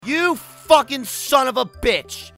rage gaming angry anger